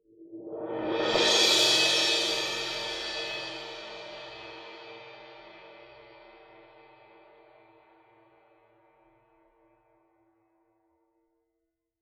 susCymb1-cresc-Short_v1.wav